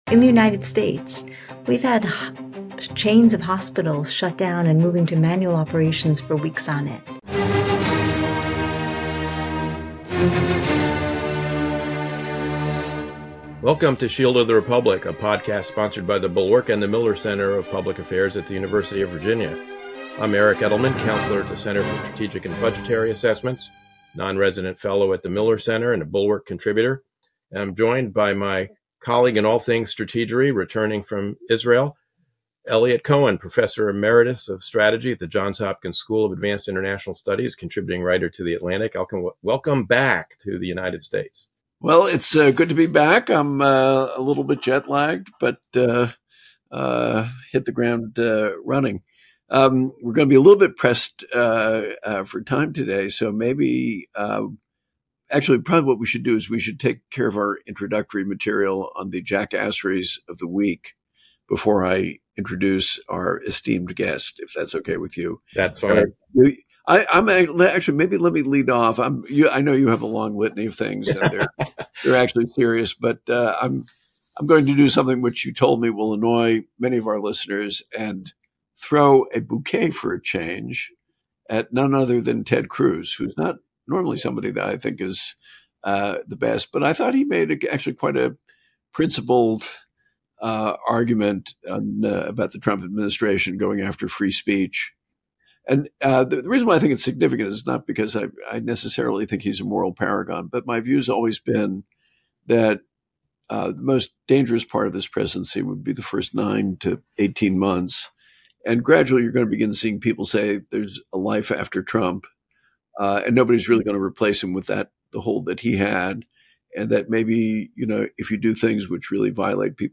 Eliot and Eric welcome Anne Neuberger, former Deputy National Security Advisor for Cyber Affairs and currently the Payne Lecturer at Stanford's Freeman Spogli Center, to discuss her latest article in Foreign Affairs.